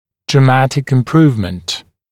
[drə’mætɪk ɪm’pruːvmənt][дрэ’мэтик им’пру:вмэнт]очень значительное улучшение